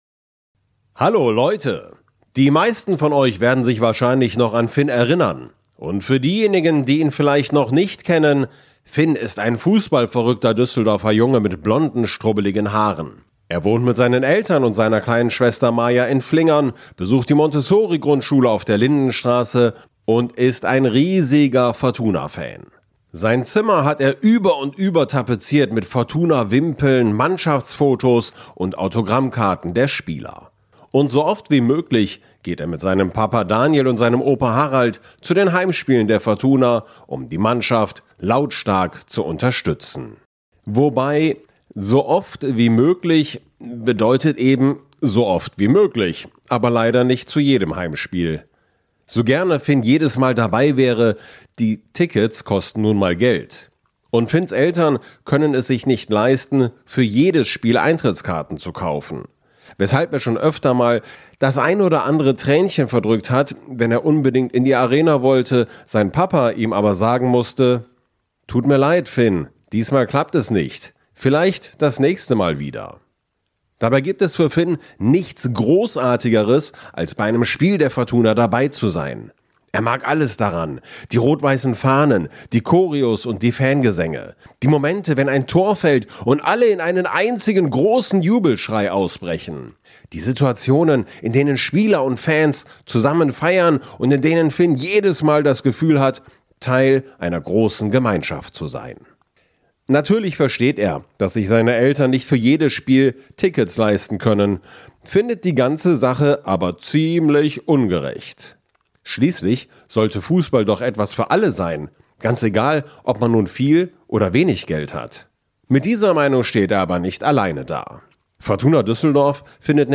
In der Hörspiel-Reihe „Finns Abenteuer" erlebt der junge Fußball-Fan Finn Geschichten rund um seinen Lieblingsverein – die Fortuna.